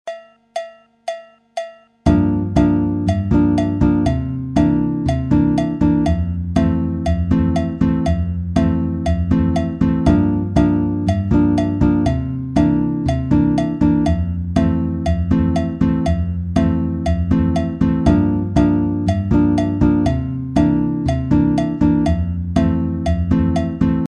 Comme support de rythme j'ai mis un clic à la croche, c'est à dire 2 pulsations par temps et cela dans le but de bien décortiquer la tourne de guitare.
batida avec une syncope